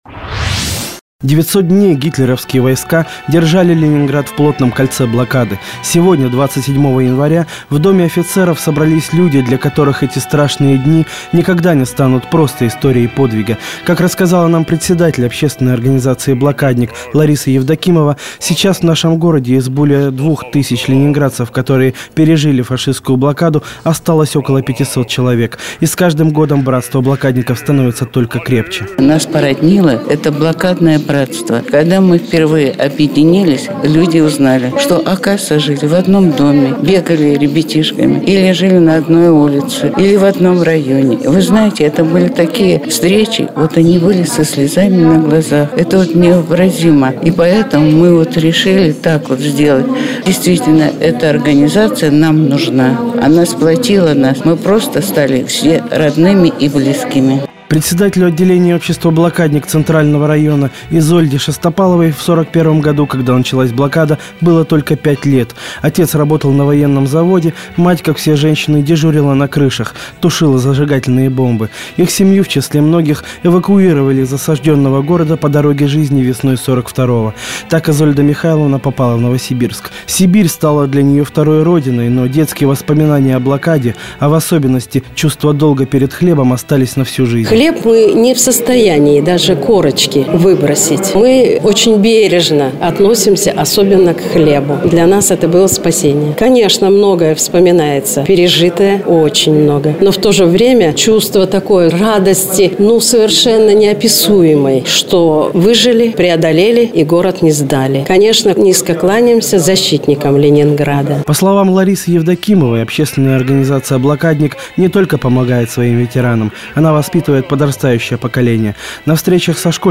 Собрание блокадников в Доме офицеров. 27 января 2010 г.
Мои репортажи, вышедшие в эфир Радио "Городская волна"